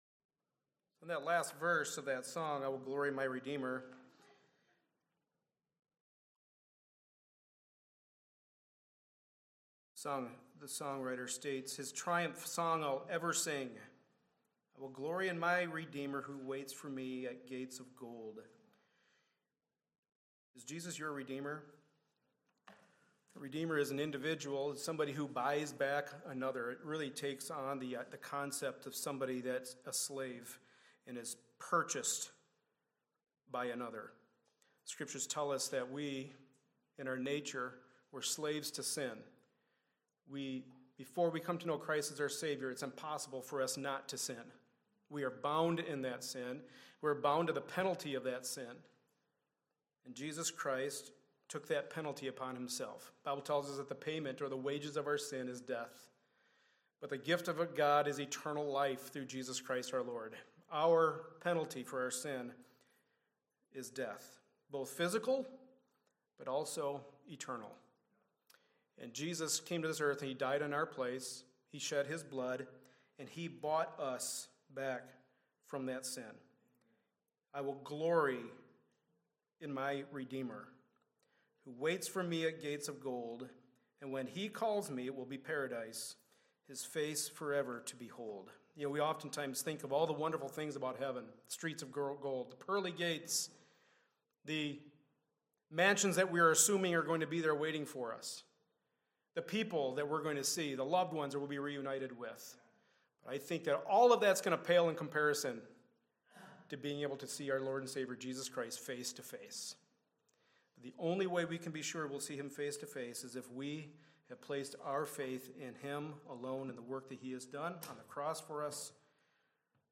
2 Timothy 2:1-6 Service Type: Sunday Morning Service A study in the Pastoral Epistles.